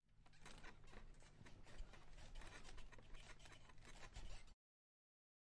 床吱吱作响
描述：一张非常吱吱作响的床。也可以听起来像沙发。 放大H6录音机
Tag: 家具 响亮 座椅 弹簧 沙发 舒适 OWI